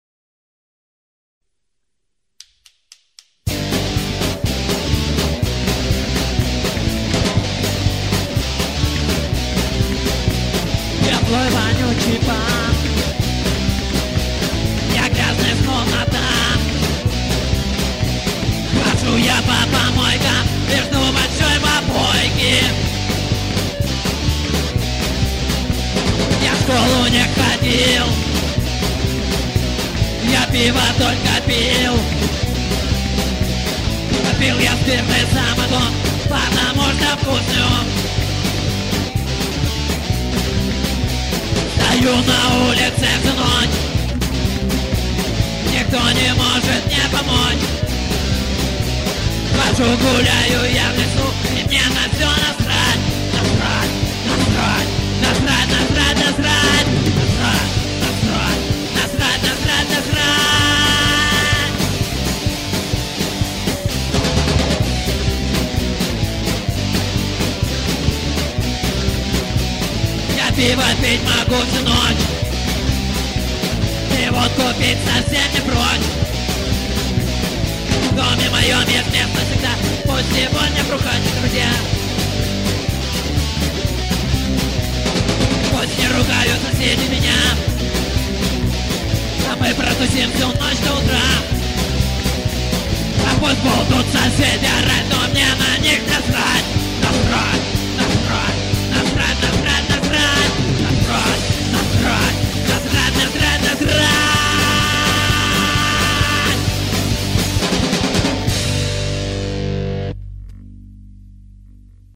1-6: Записано на студии "Рай" весной 2004г.